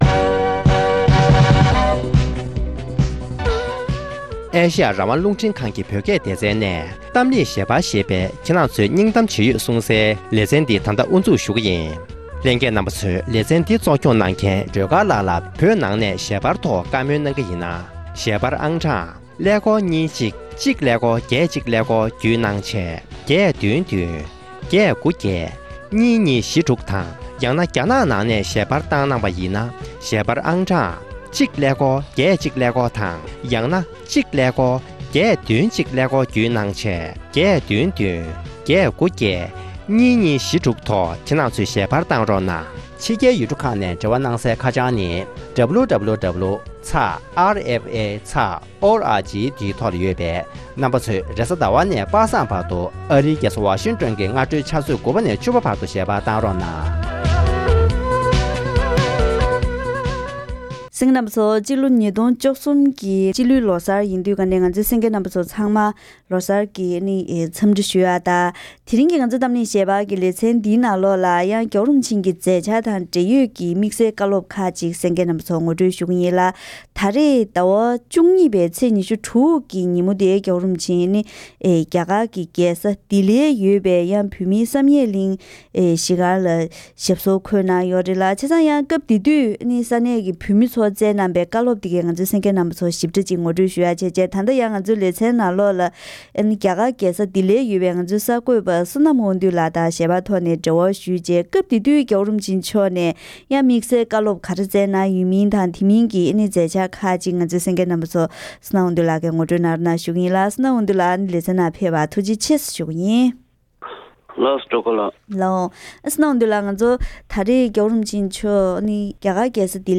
བོད་མིའི་བླ་ན་མེད་པའི་དབུ་ཁྲིད་སྤྱི་ནོར་༧གོང་ས་༧སྐྱབས་མགོན་ཆེན་པོ་མཆོག་ཉེ་འཆར་རྒྱ་གར་རྒྱལ་ས་ལྡི་ལིའི་བོད་སྒར་བསམ་ཡས་གླིང་བཙུགས་ནས་ལོ་༥༠འཁོར་བའི་དུས་དྲན་གྱི་མཛད་སྒོར་ཆིབས་བསྒྱུར་གྱིས་བཀའ་སློབ་གནང་ཡོད་པ་རེད།